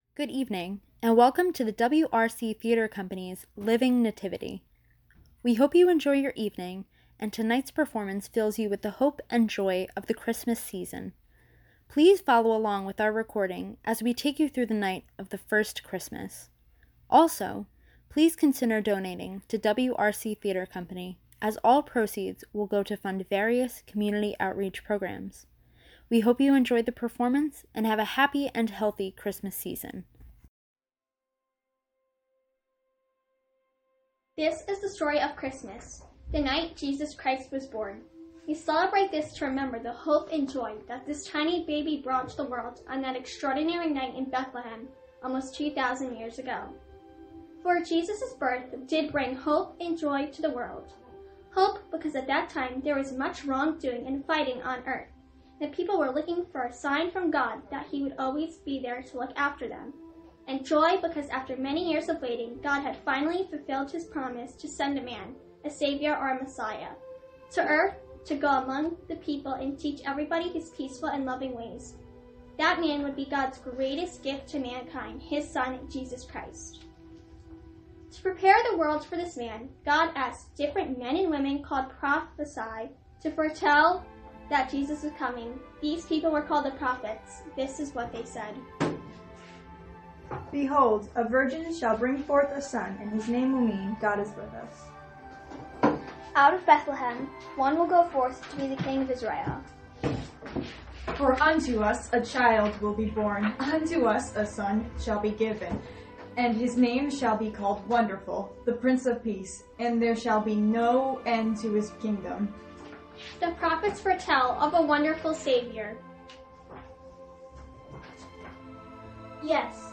Thank you so much for attending our Drive-Thru Living Nativity performance.